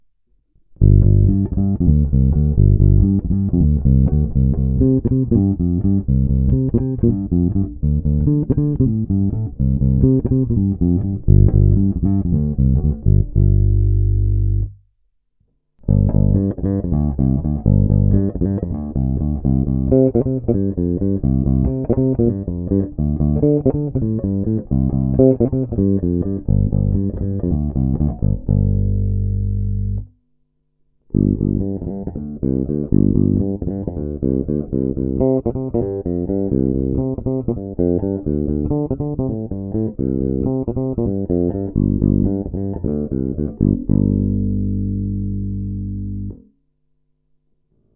Následující zvukové ukázky jsou provedeny přímo do zvukové karty a kromě normalizace ponechány bez jakýchkoli úprav. Použité jsou ocelové hlazené struny D'Addario XL Chromes ECB81 (recenze) ve skoro novém stavu.
Následující ukázky obsahují nahrávky v pořadí krkový snímač - oba snímače - kobylkový snímač:
Tónová clona na půlku